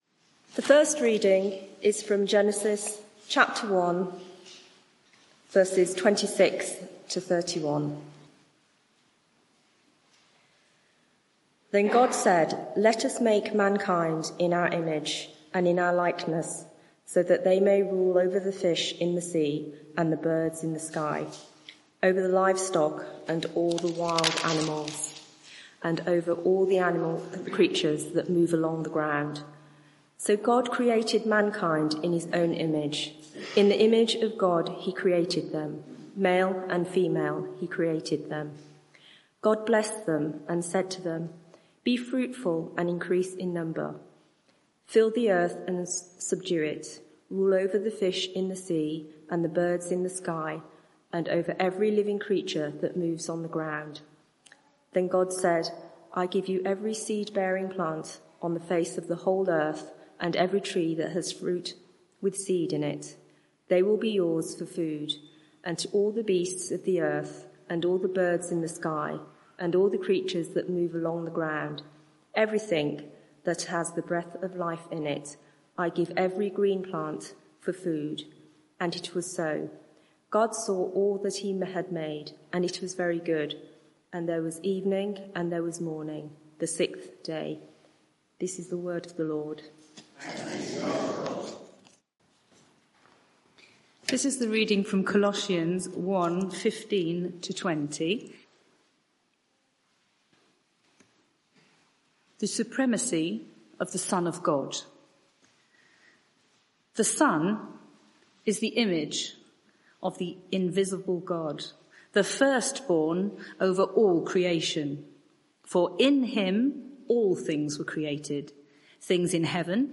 Media for 6:30pm Service on Sun 06th Oct 2024 18:30 Speaker
Sermon (audio)